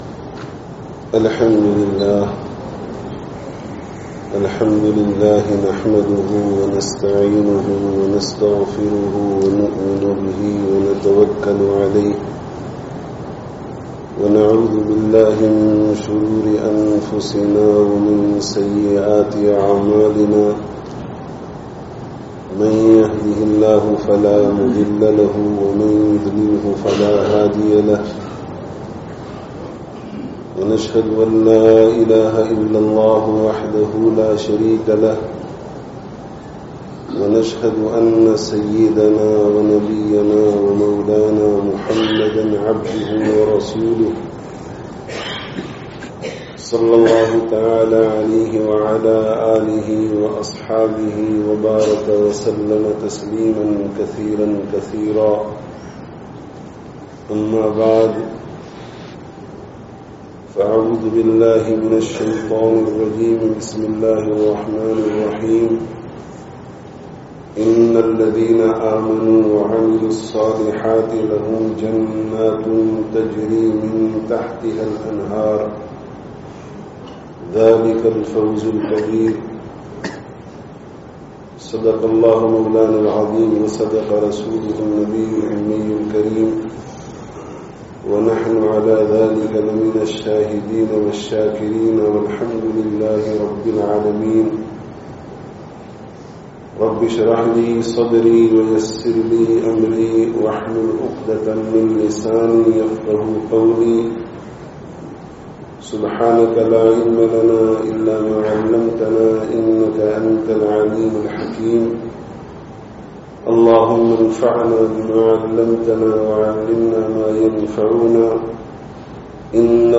Dīn kī Mehnat (Jame Masjid, Lusaka, Zambia 23/08/18)